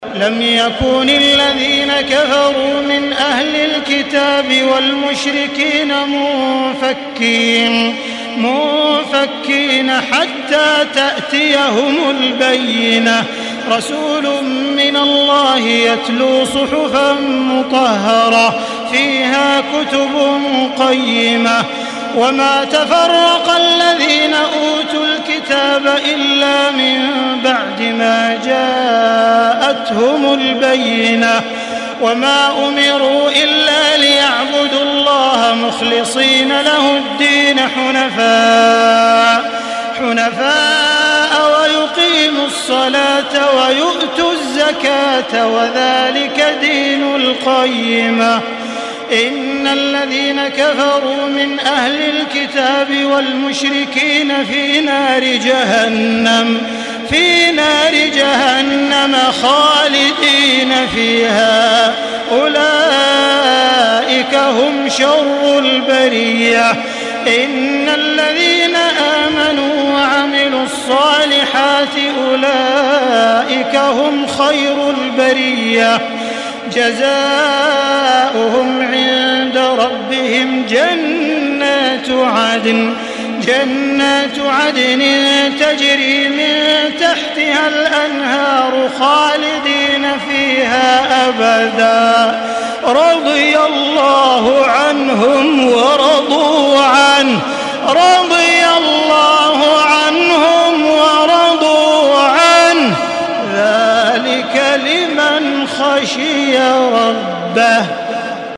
Surah Al-Bayyinah MP3 by Makkah Taraweeh 1435 in Hafs An Asim narration.
Murattal